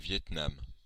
Ääntäminen
US : IPA : [ˌvi.ət ˈnɑm]